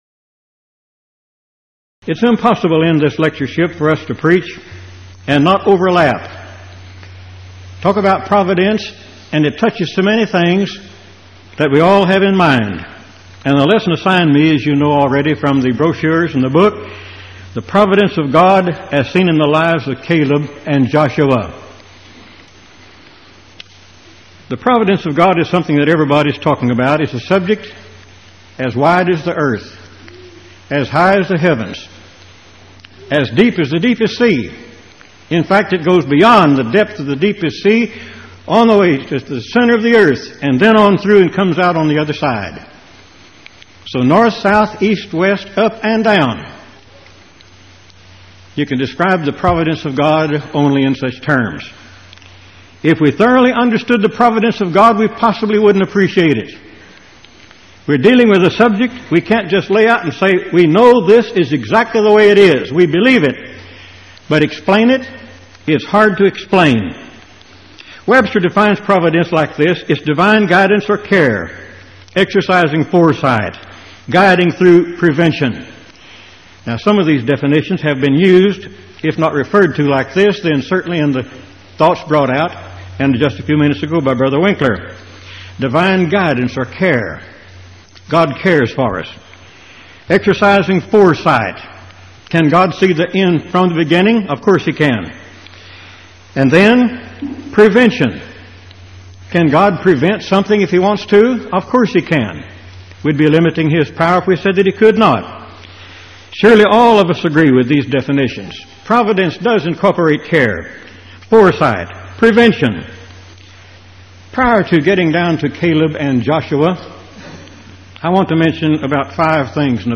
Event: 1989 Power Lectures